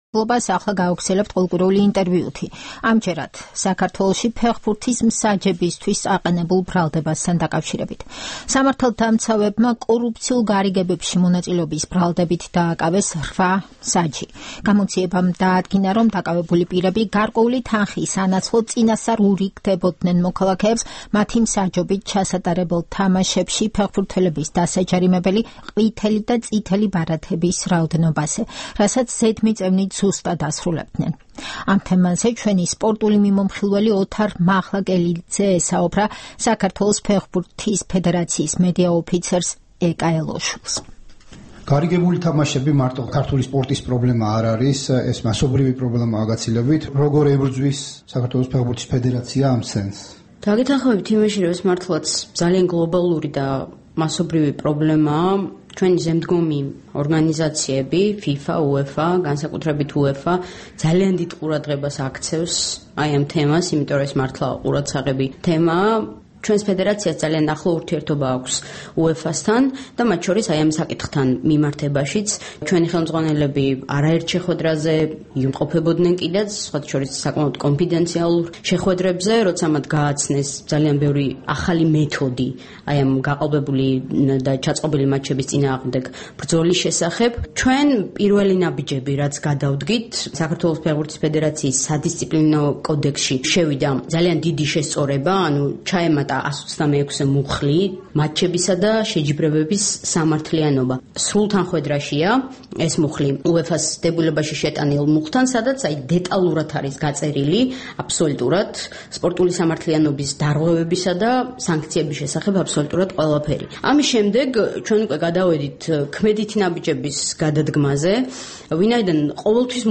ინტერვიუ